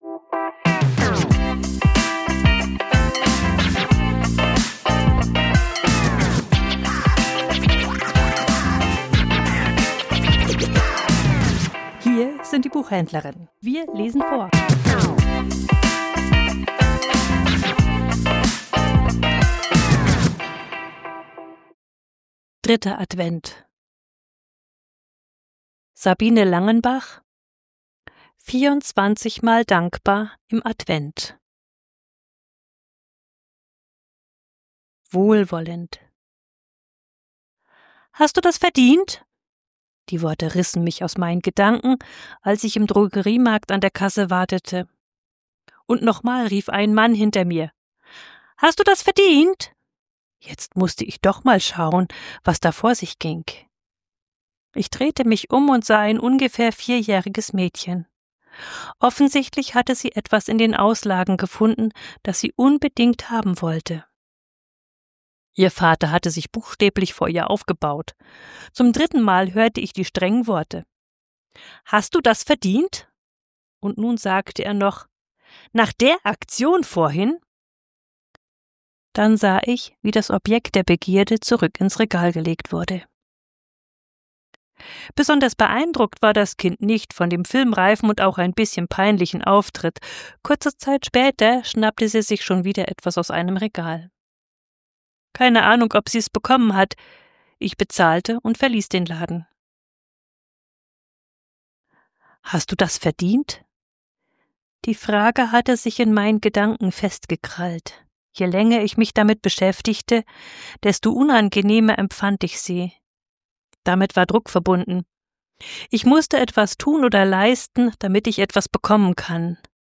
Vorgelesen: Wohlwollend